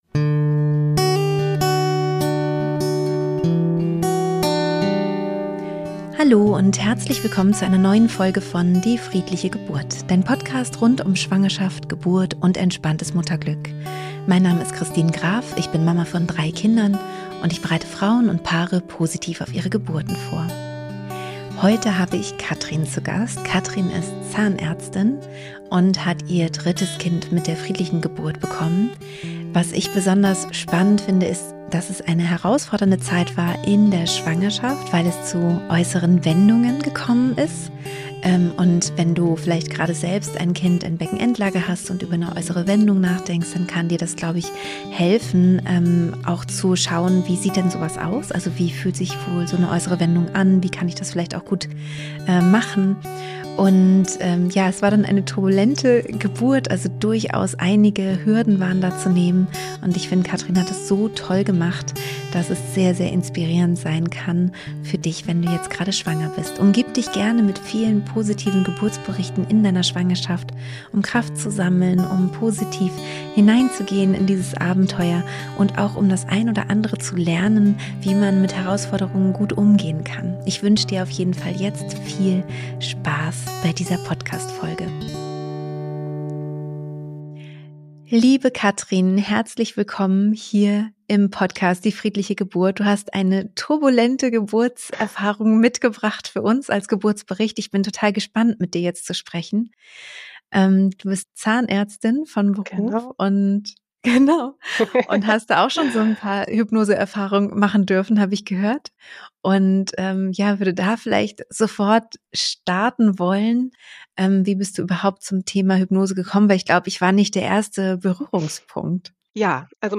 1 370 – Geburtszeitraum statt ET – Ein wichtiger Unterschied – Interview